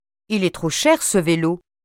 les sons [ o ] bureau, tôt [ ɔ ] bonne, prof